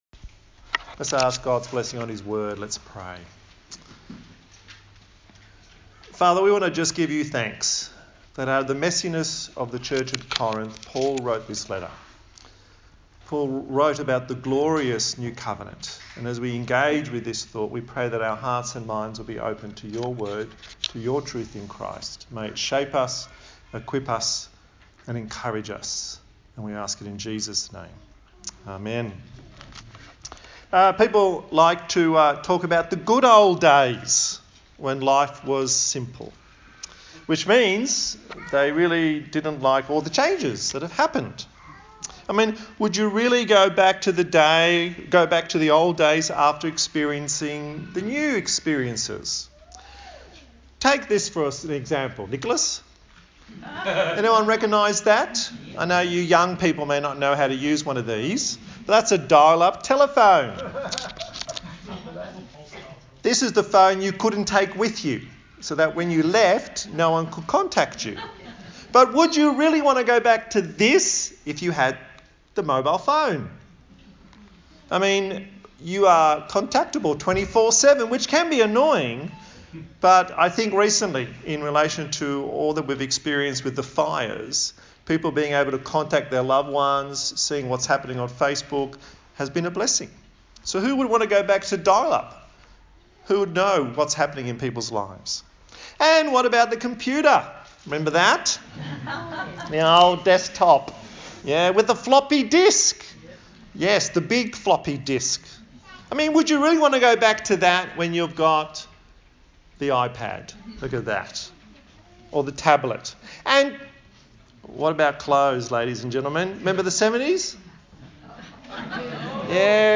A sermon in the series on the book of 2 Corinthians